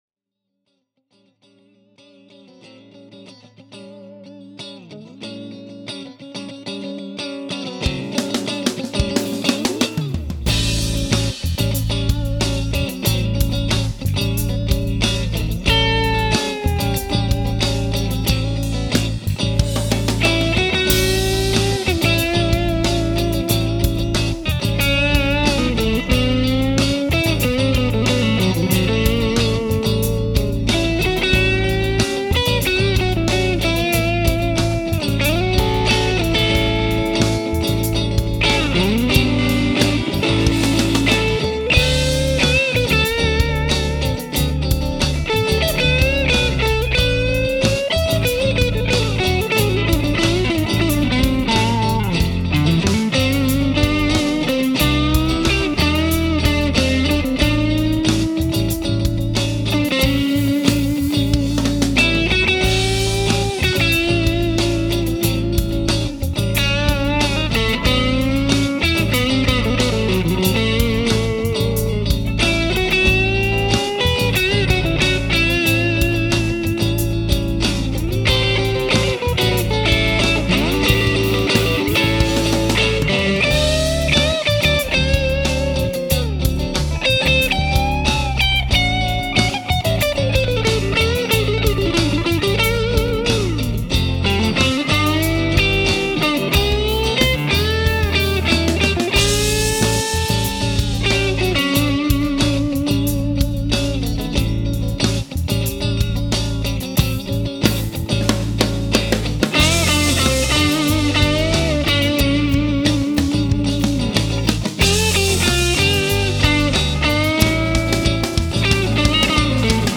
You get that fizz from EL84’s anyway, which happen to power this amp, but those NOS tubes really mellowed out the fizz, and provided a richness in the tone of that amp that I had not ever heard come from it! I was so amazed, that I used that amp on a new song I’ve been working on called, “Strutter.” I may actually call it “Stratter,” as I recorded it with my Strat, but I’m still undecided…